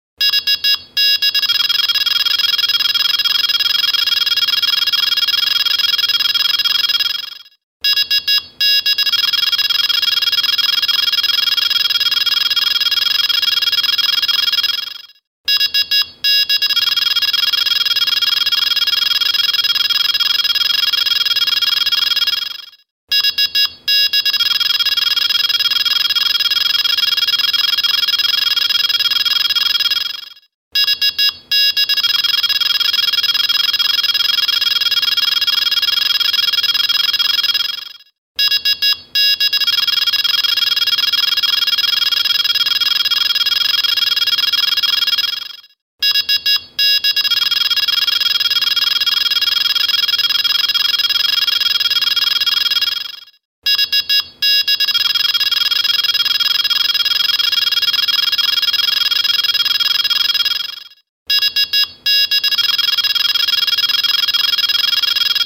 Ringtone | Delkim
Delkim_Warble.mp3